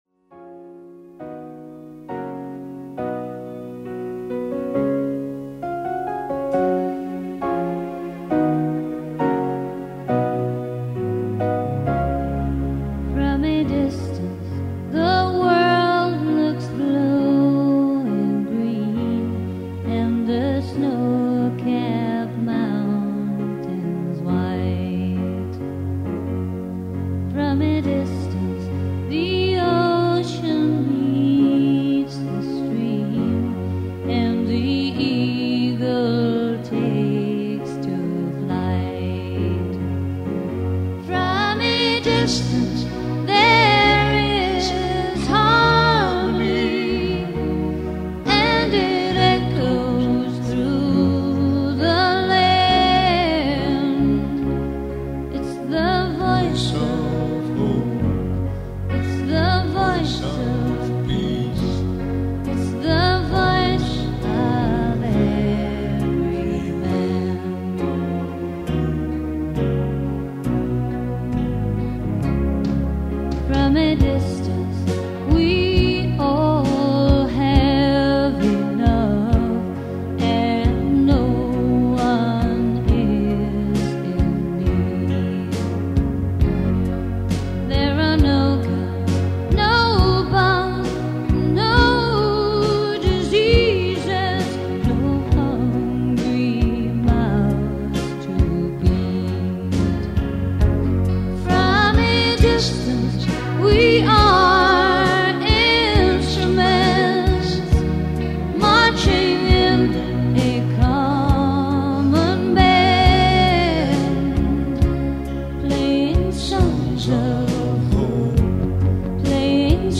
made on a four track cassette recorder.
singing backing vocals